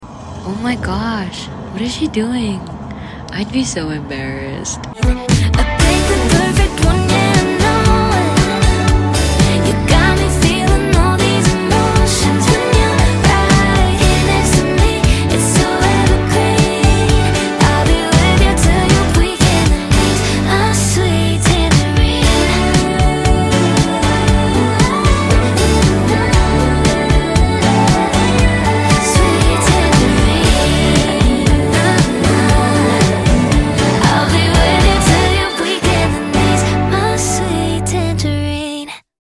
sweet summer track to fall in love to